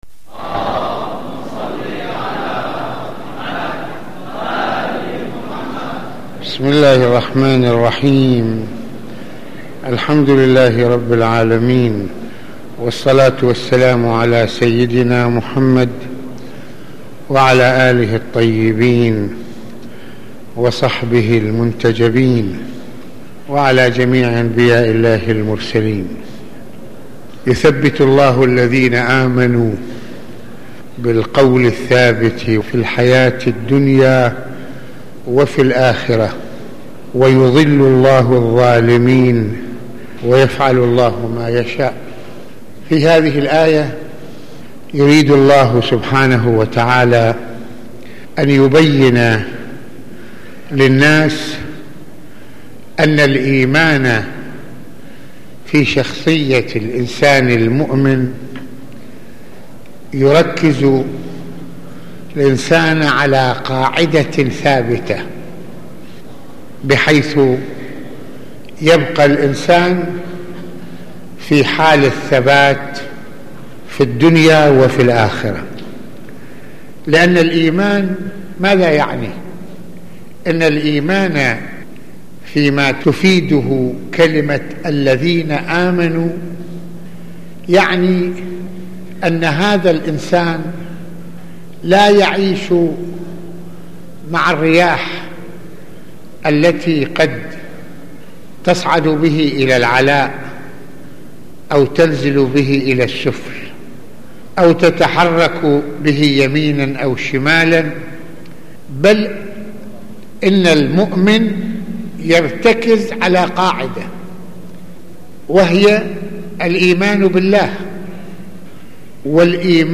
- المناسبة : موعظة ليلة الجمعة المكان : مسجد الإمامين الحسنين (ع) المدة : 26د | 36ث المواضيع : آيات للتذكر والتفكير لمعرفة الله تعالى - مسؤوليتنا امام الله تعالى - الايمان يركز الانسان على قاعدة ثابتة - ماذا يعني الايمان ؟